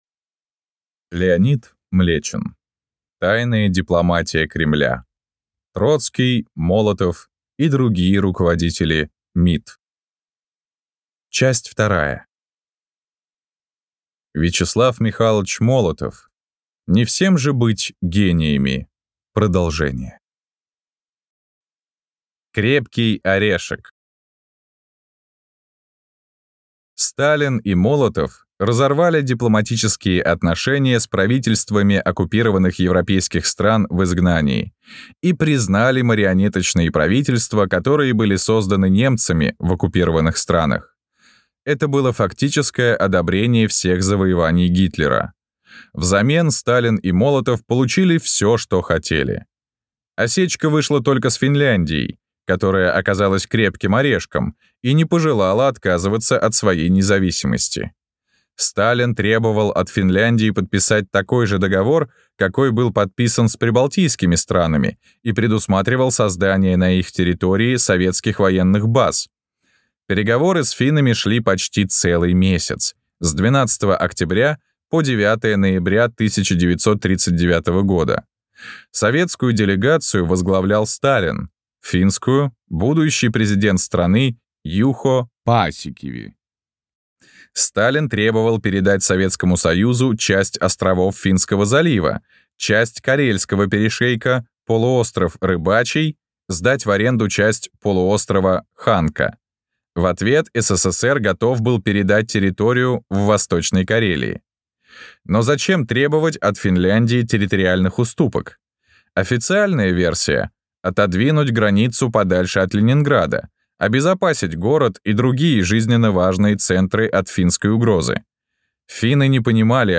Аудиокнига Тайная дипломатия Кремля. Часть 2 | Библиотека аудиокниг